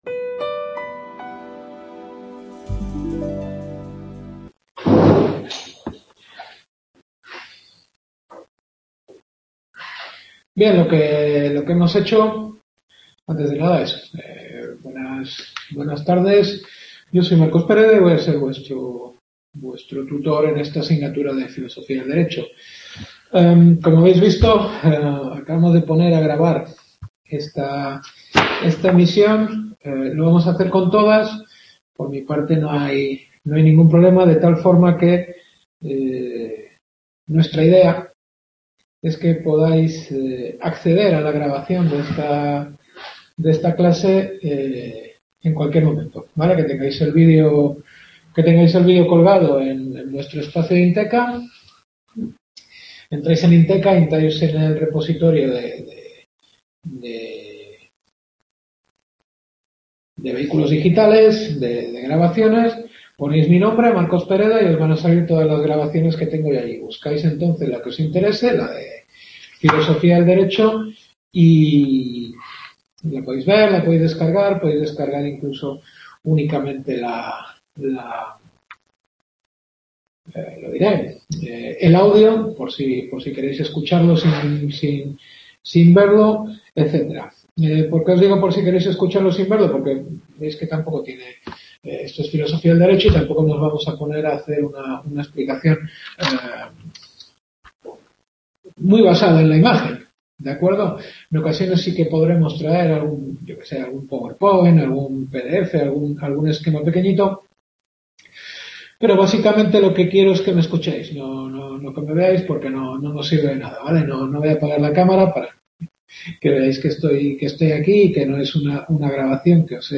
Primera clase.